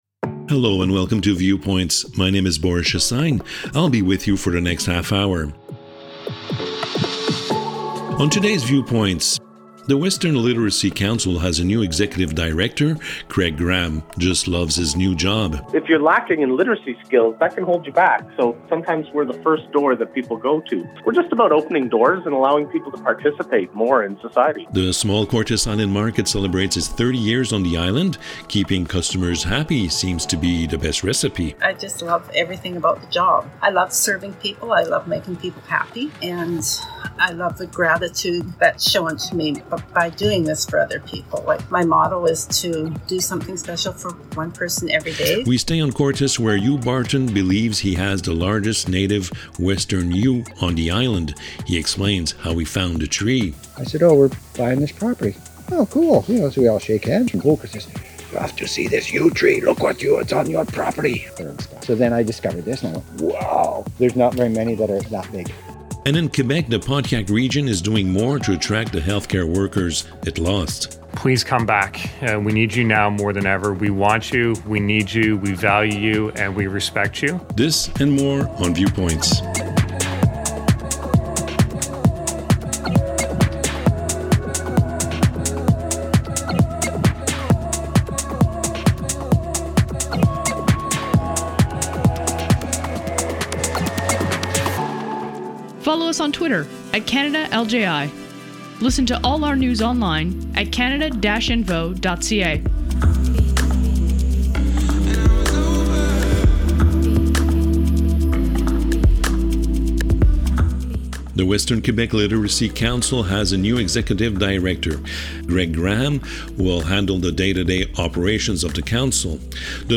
The Community Radio Fund of Canada produces a new weekly radio series called Viewpoints, a 30 minute news magazine aired on 30 radio stations across Canada. Viewpoints provides an overview of what’s happening across the country, thanks to some 20 radio correspondents working for the Local Journalism Initiative in British Columbia, Ontario, Québec, New Brunswick and Nova Scotia.